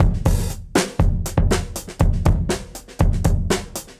Index of /musicradar/dusty-funk-samples/Beats/120bpm